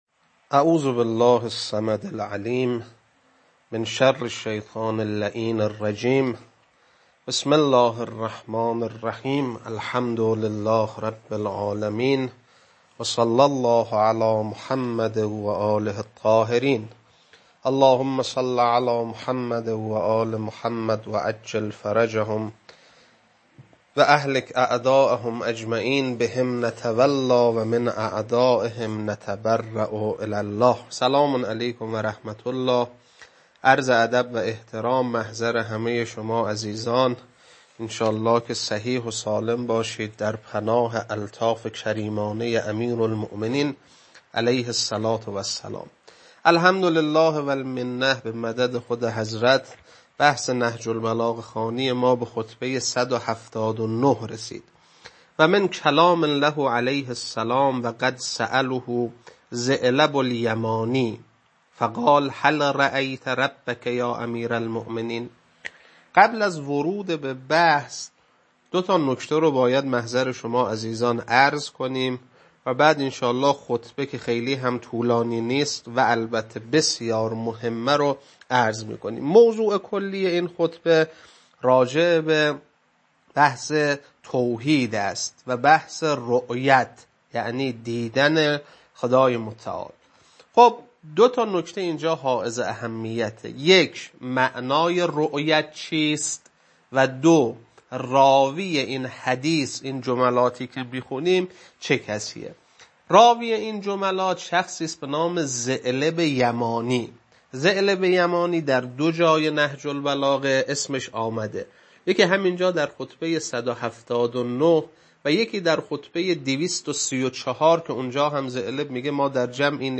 خطبه-179.mp3